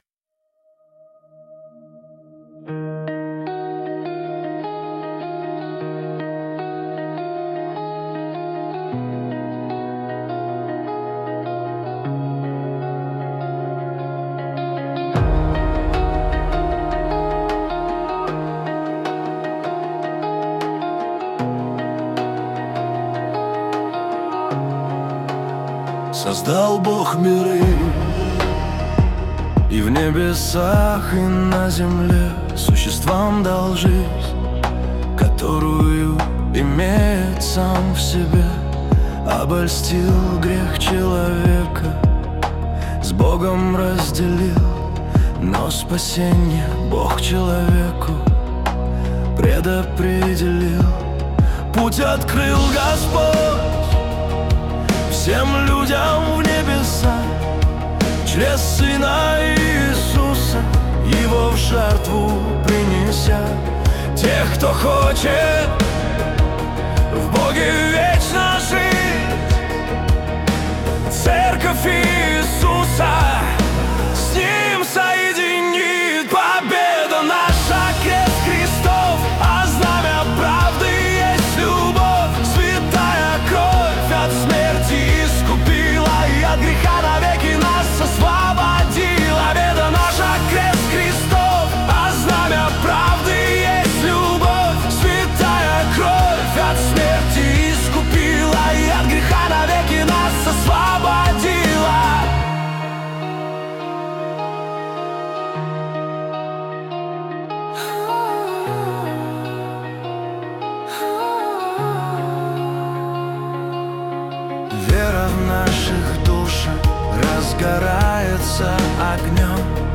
песня ai
161 просмотр 1525 прослушиваний 73 скачивания BPM: 78